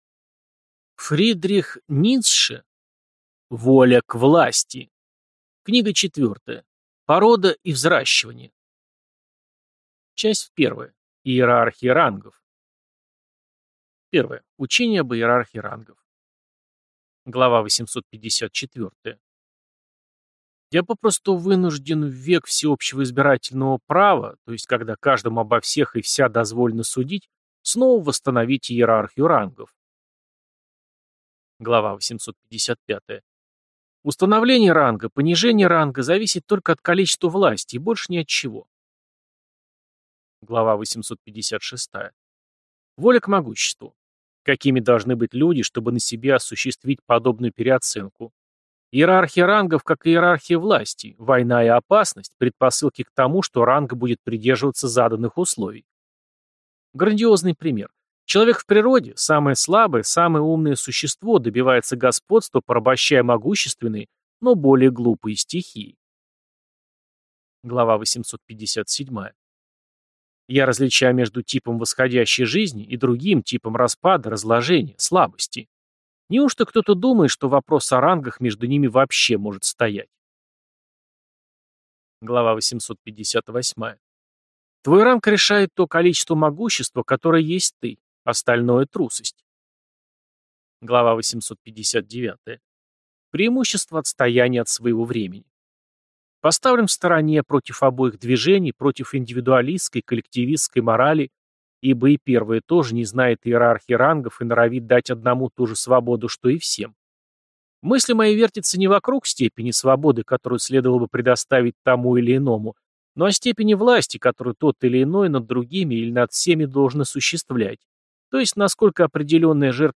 Аудиокнига Воля к власти. Книга четвертая. Порода и взращивание | Библиотека аудиокниг